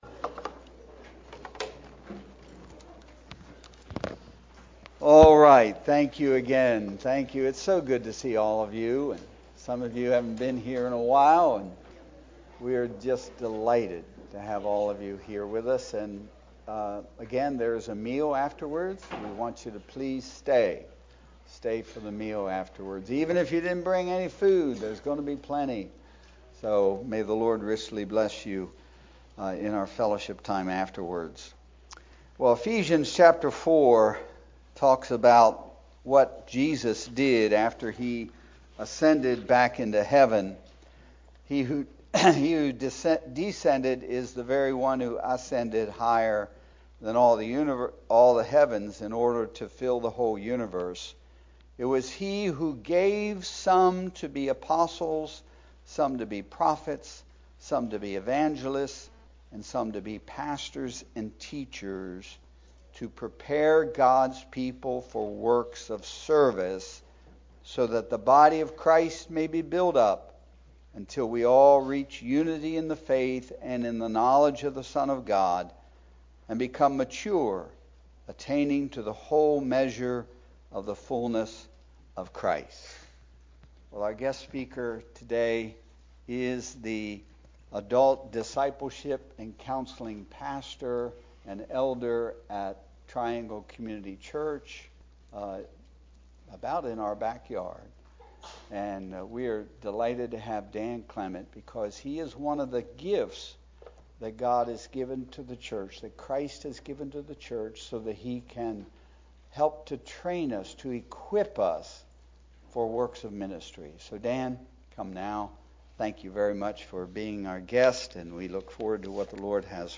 Anniversary Service